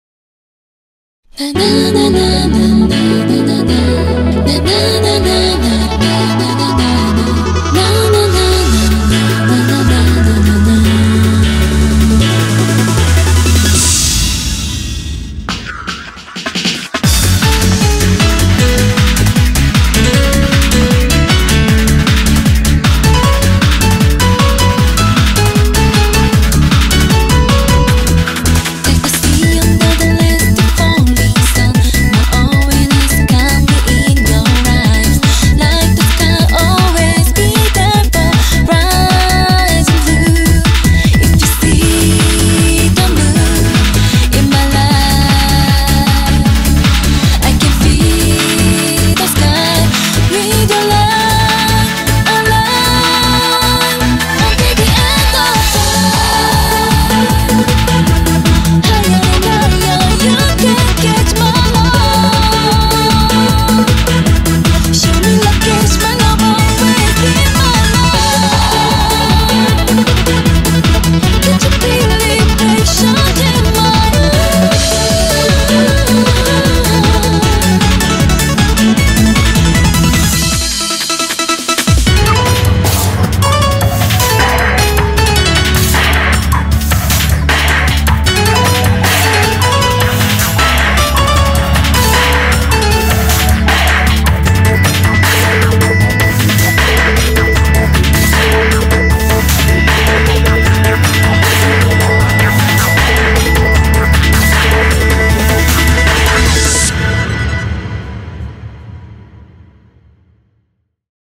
BPM155
Audio QualityPerfect (High Quality)
has lots of arpeggiated synth work